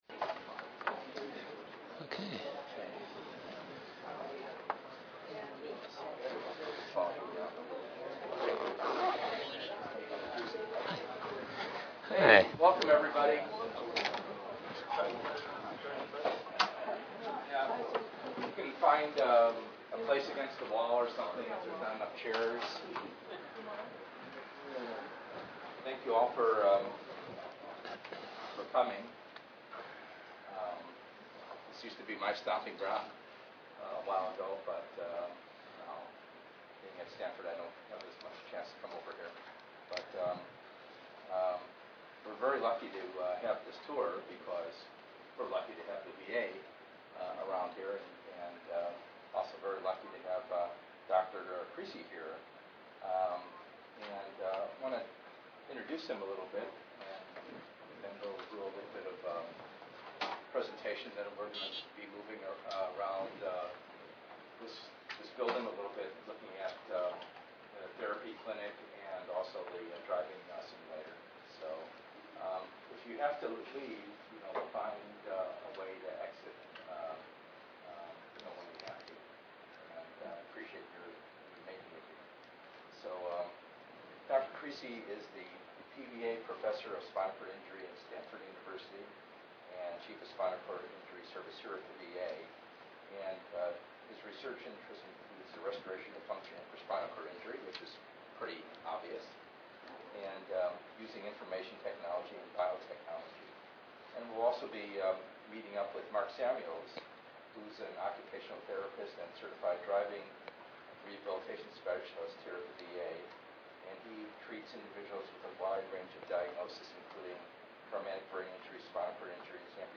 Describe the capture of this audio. This lecture will be held at the VA Palo Alto Health Care System campus in the Spinal Cord Injury Service.